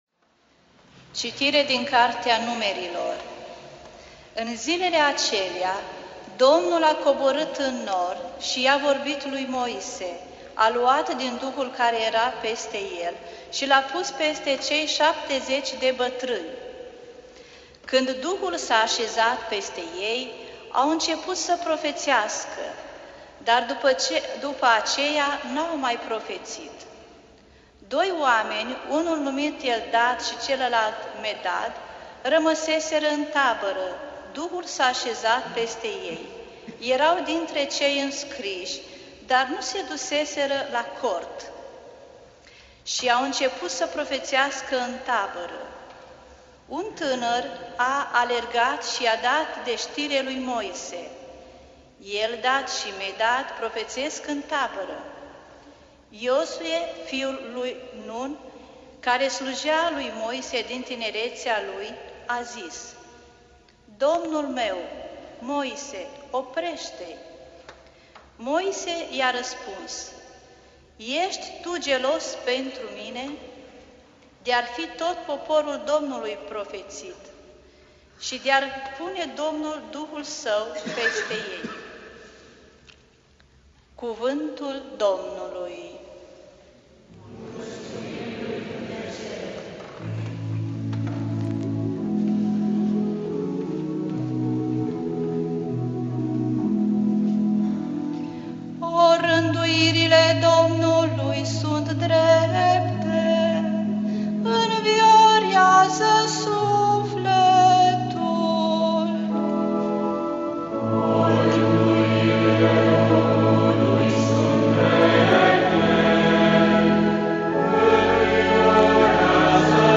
Biblioteca - Predici la Radio Iasi